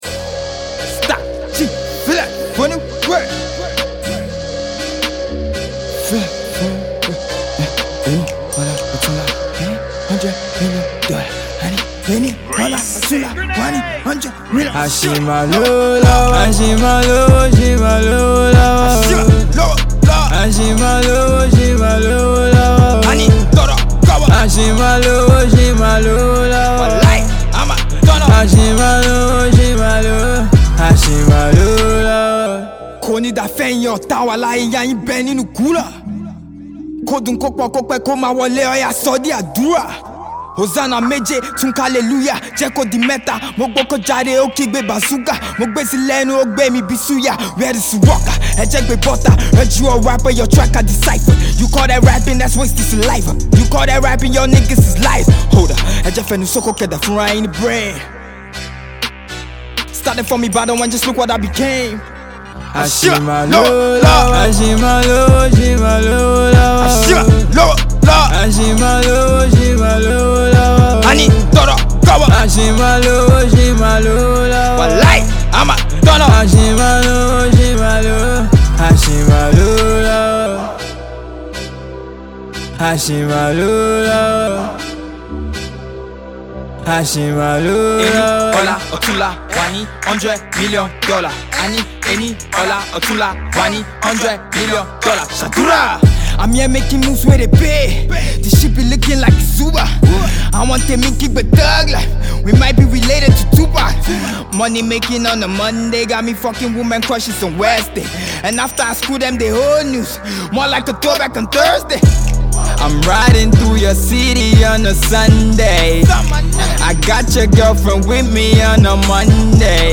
a trap song